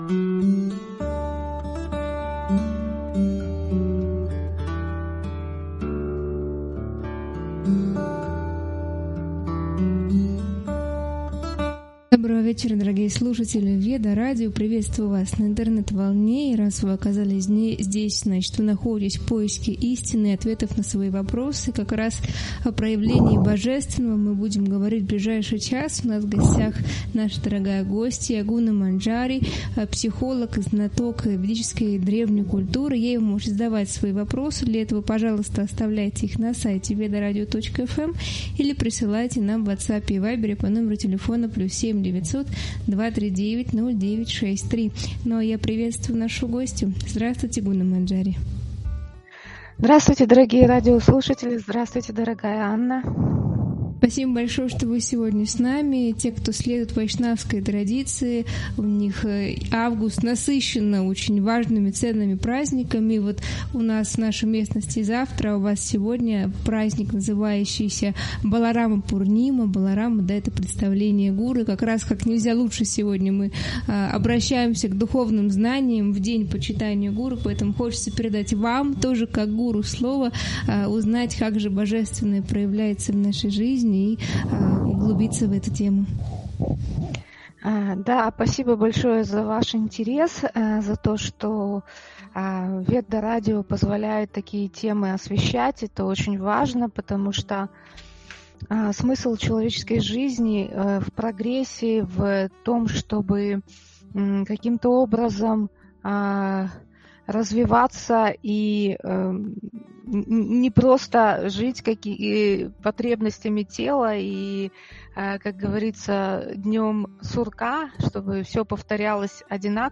Эфир посвящён духовному пути и преодолению материализма, роли учителя и дисциплины ученика. Обсуждается, как сомнения и карма влияют на развитие, почему важно глубокое изучение священных писаний и практика знаний.